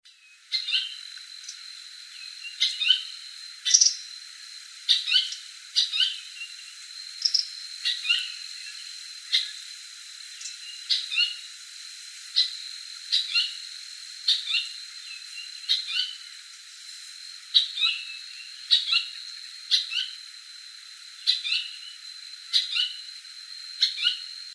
White-eyed Foliage-gleaner (Automolus leucophthalmus)
Life Stage: Adult
Location or protected area: Reserva Privada y Ecolodge Surucuá
Condition: Wild
Certainty: Recorded vocal
TICO-TICO-OJO-BLANCO.MP3